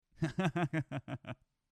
wodlaugh_mixdown.mp3